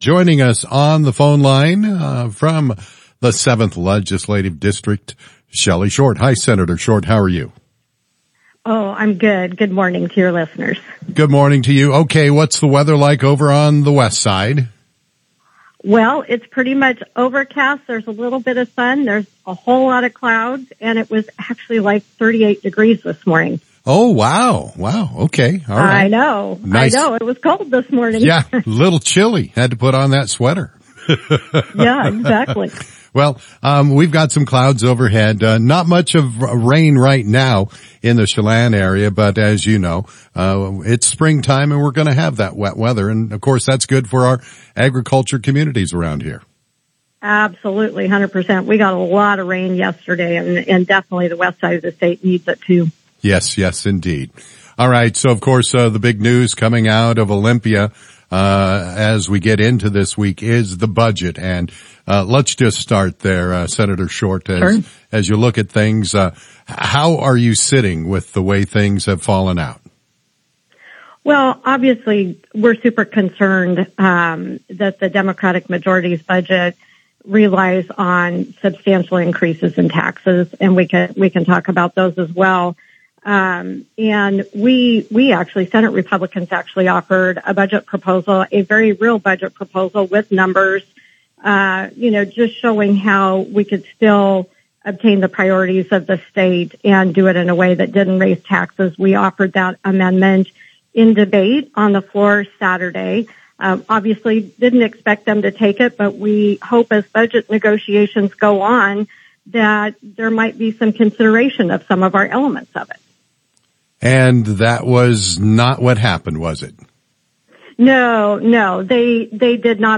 Senator Shelly Short joins KOZI Radio to discuss the state budget and tax proposals.